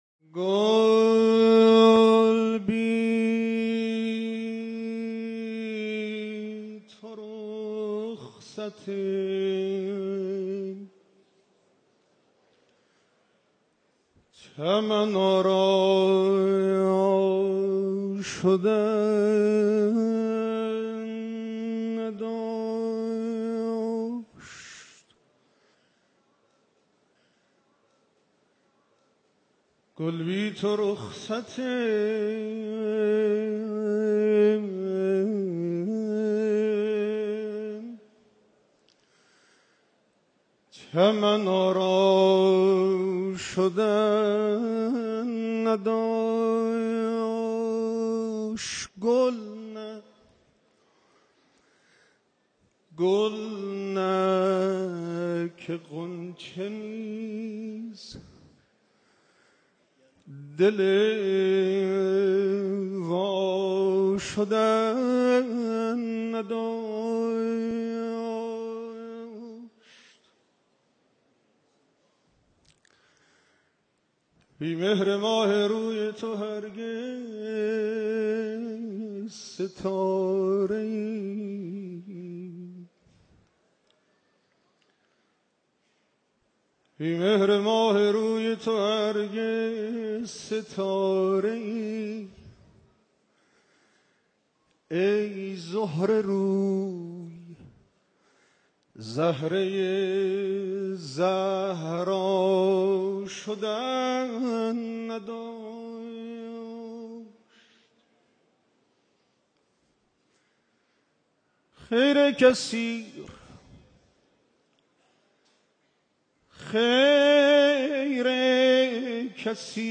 شعر خوانی | گل بی تو رخصت چمن آرا شدن نداشت
در شب ولادت حضرت فاطمه (س) | محضر رهبرانقلاب اسلامی | حسینیه امام خمینی(ره)